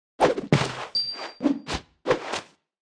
Media:Sfx_Anim_Ultra_Greg.wavMedia:Sfx_Anim_Ultimate_Greg.wav 动作音效 anim 在广场点击初级、经典、高手、顶尖和终极形态或者查看其技能时触发动作的音效
Sfx_Anim_Super_Greg.wav